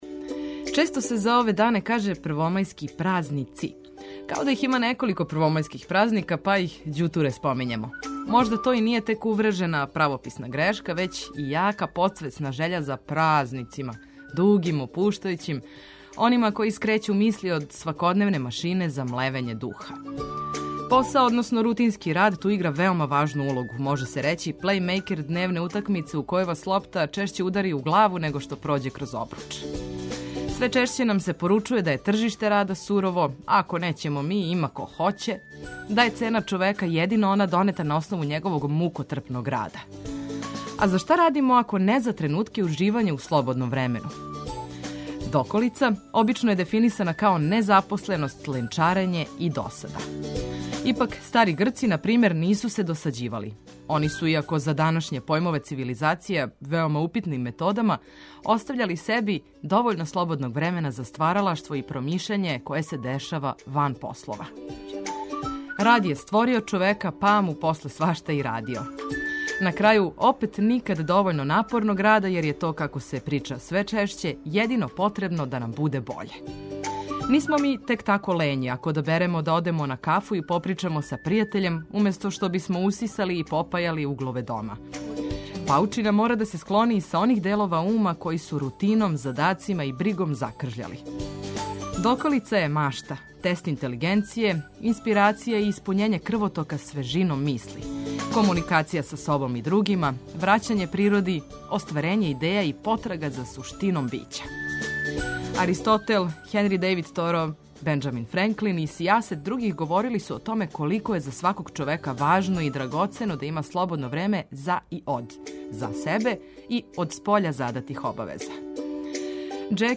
Уз музику која мами осмех, инспиративне теме и важне информације, започињемо ову (нерадну) среду.